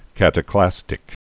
(kătə-klăstĭk)